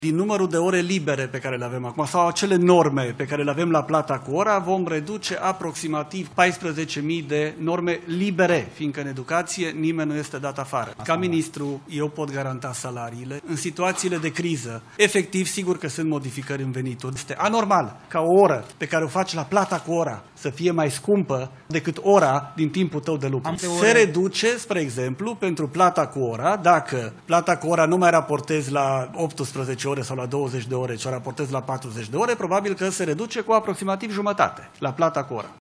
Ministrul Educației, Daniel David, dă asigurări că nimeni nu va fi dat afară din sistem, în urma măsurilor anunțate. În schimb, creșterea normei didactice și reducerea sumei cu care se face plata cu ora, în afara normei, va duce la reducerea veniturilor.